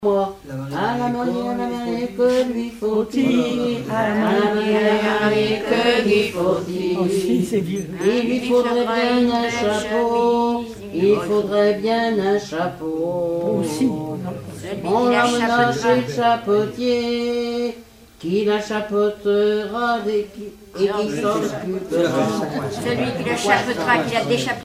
circonstance : fiançaille, noce
Genre énumérative
Regroupement de chanteurs du canton
Pièce musicale inédite